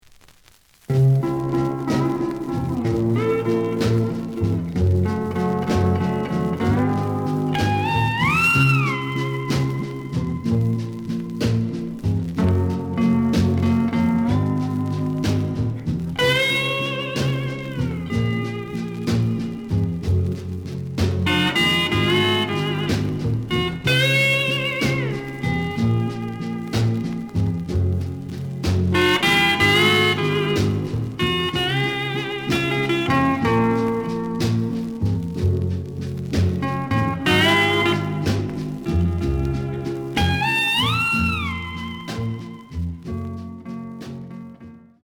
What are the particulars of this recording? The audio sample is recorded from the actual item. Looks good, but slight noise on A side.)